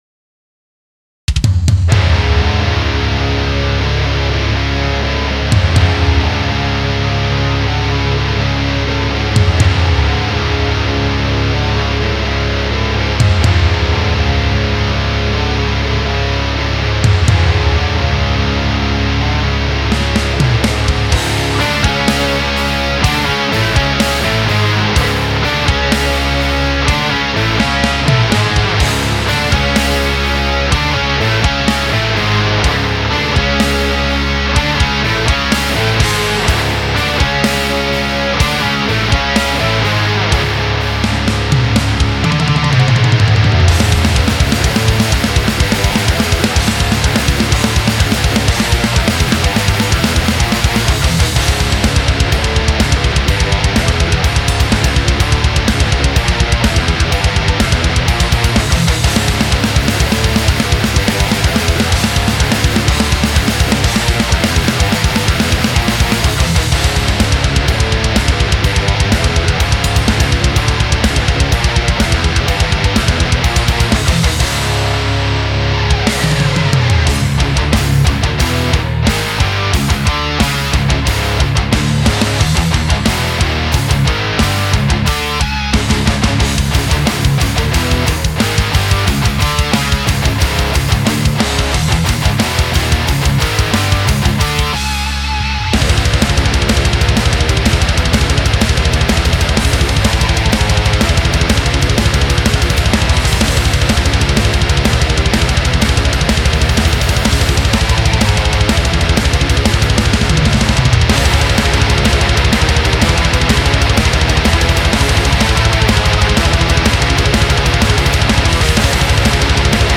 Old School Death Metal sucht Mix Review
Bei der Kick schließe ich mich an, die kann noch ein Pfund mehr vertragen aber der Rest ist schon sehr geil. Vocals sollten doch machbar sein.